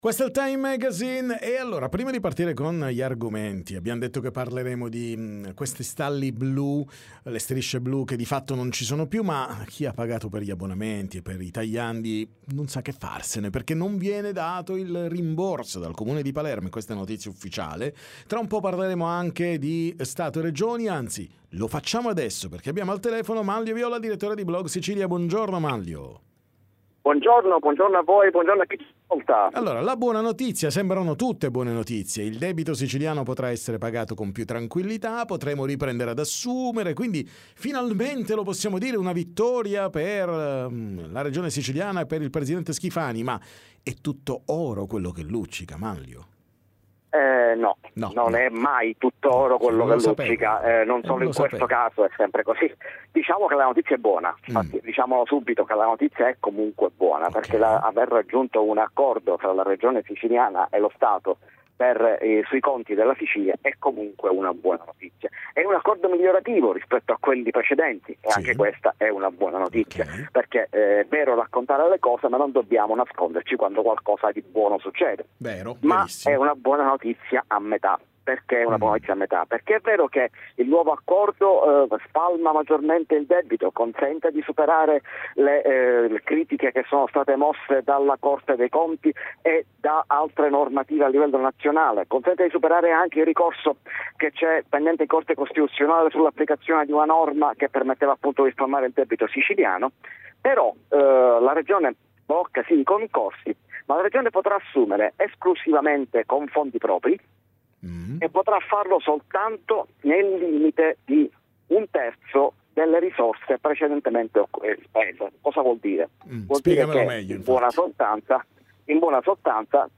Interviste Time Magazine